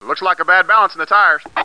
1 channel
WOBTIRE2.mp3